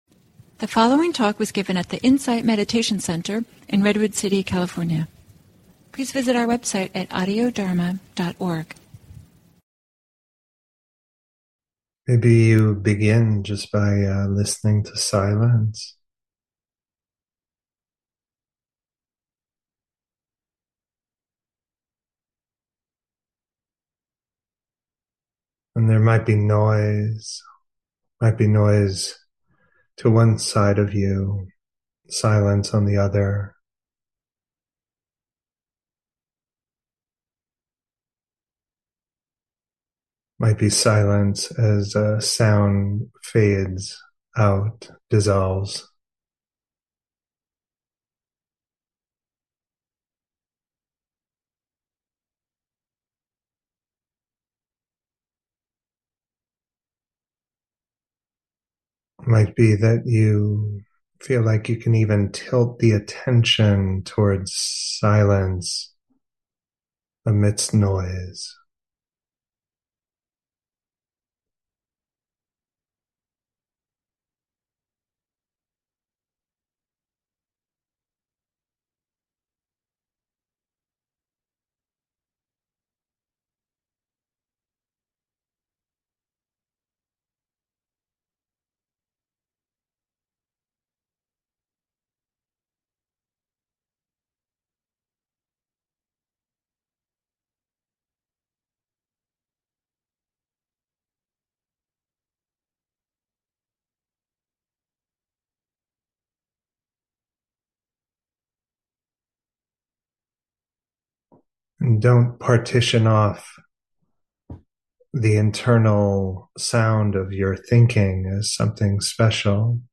Guided Meditation: Grounding and Compassion